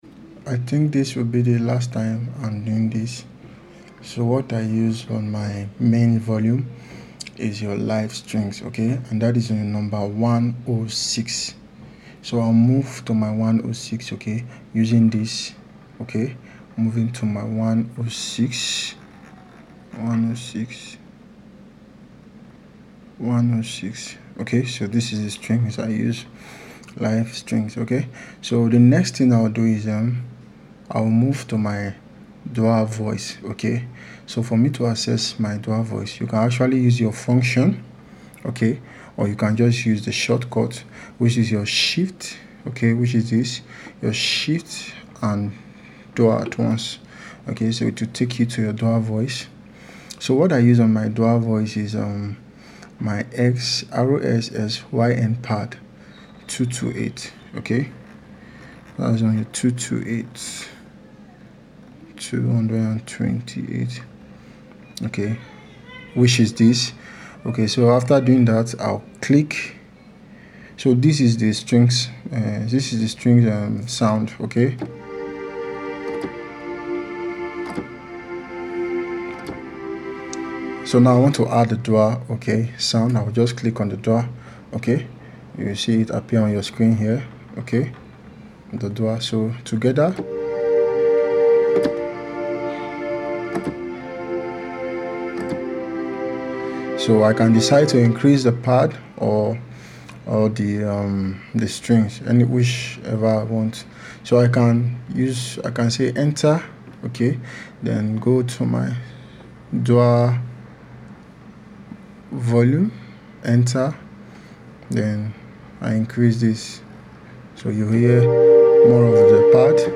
Strings and pad settings for Yamaha Psr-E473